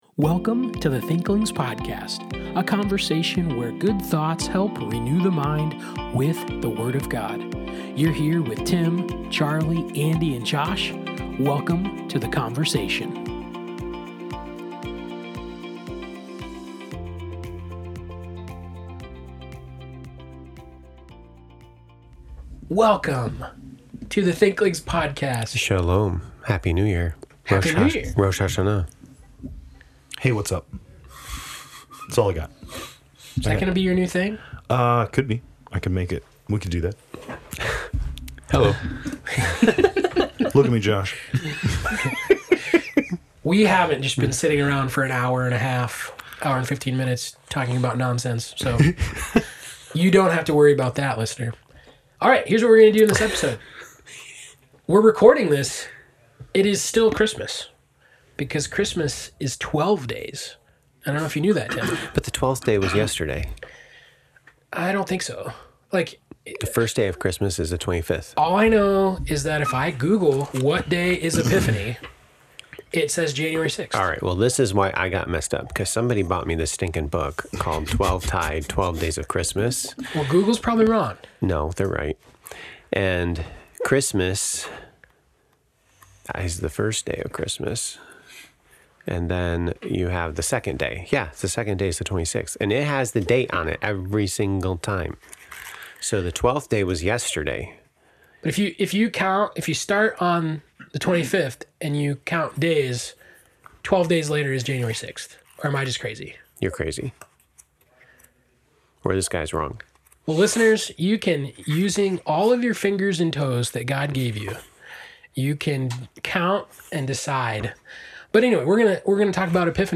In this episode, the Thinklings mark Epiphany (January 6)—the close of the Twelve Days of Christmas—with a thoughtful and forward-looking conversation. Alongside seasonal reflection, the Thinklings also talk about goals for 2026, considering how intention, wisdom, and faith shape the year ahead.